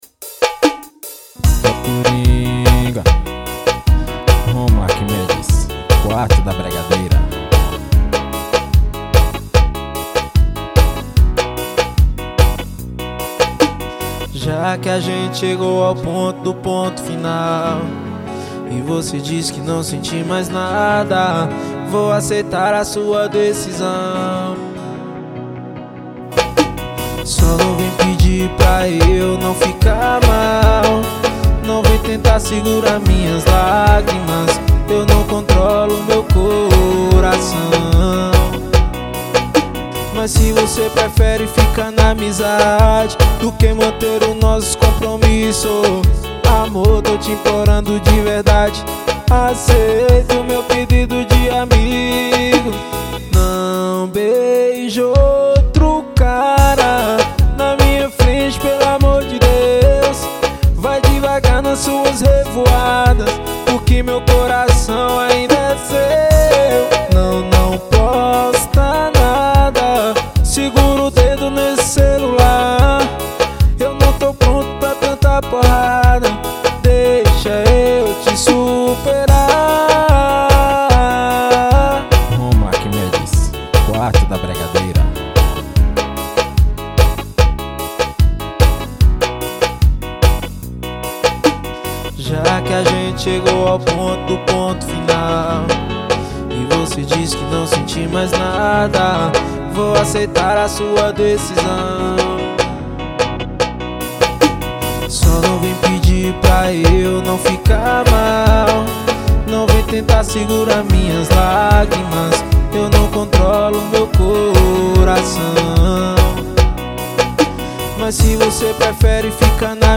EstiloBregadeira